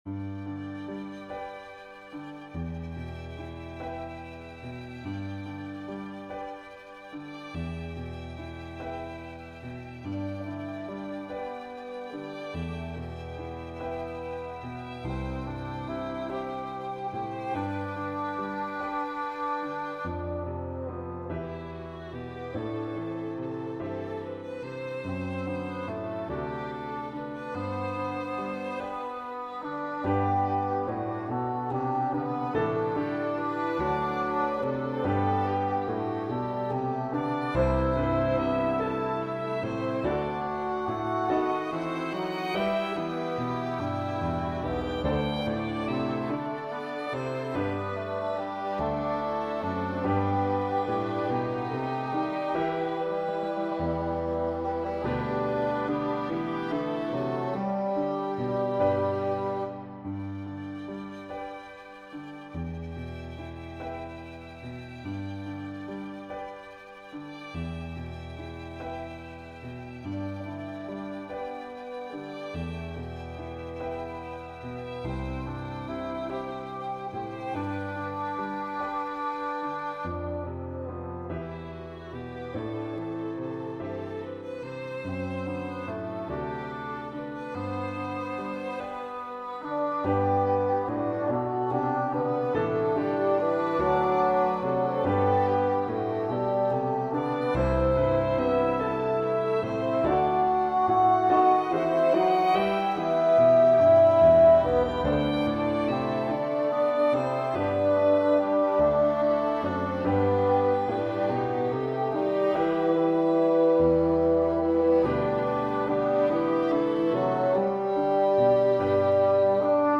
Duet, Violin Duet/Violin Ensemble Member(s)
I wanted to feature both the alto and the tenor without either one taking a back seat to the other. And why not throw in a couple of violins, as well, with a complementary part?